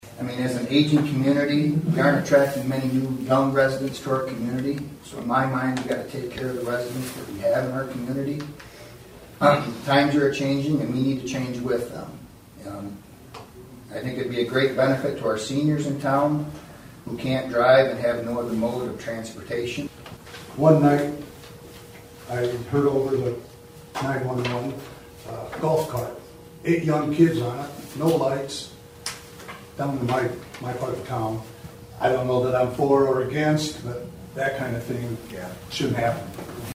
As expected, there were mixed opinions from audience members.